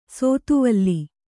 ♪ sōtuvalli